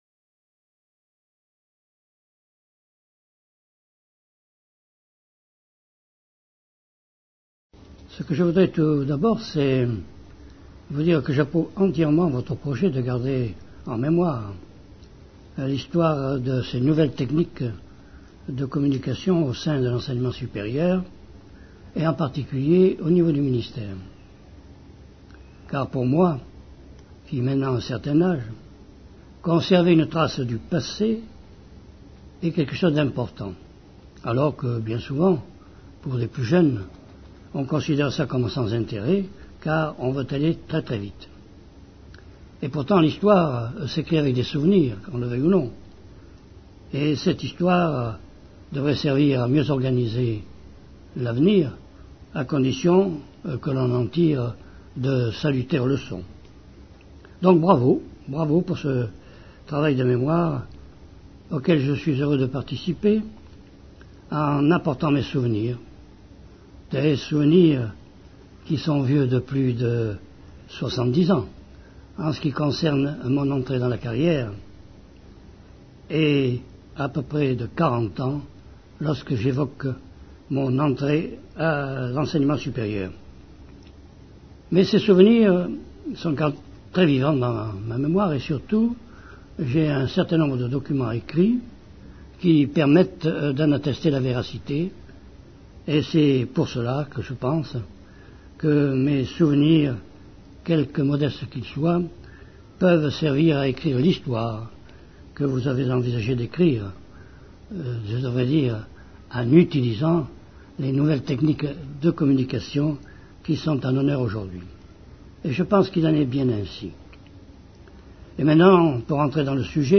Entretien extrait de la série "politique publique" - Mémoires Croisées